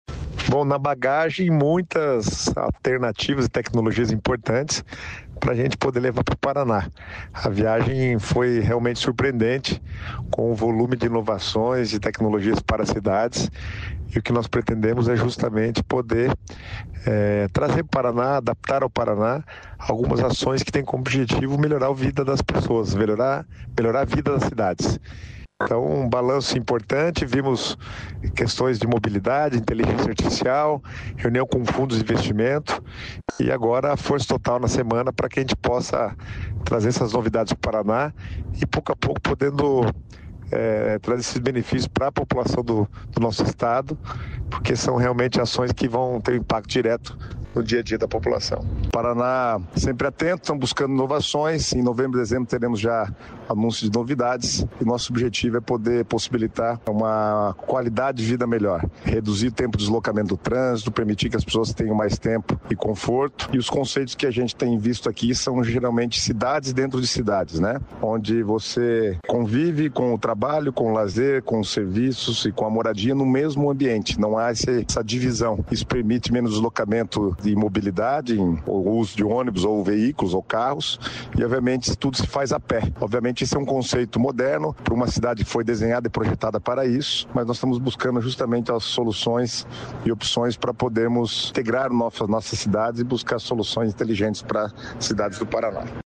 Sonora do secretário estadual das Cidades, Guto Silva, sobre a missão na Arábia Saudita